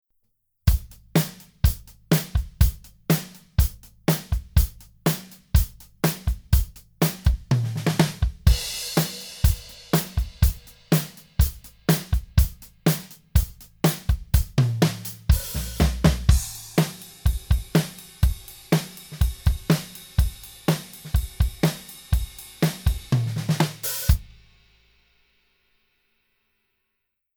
Here’s an audio sample of a drum recording made without Drumagog 5.
original-mix.mp3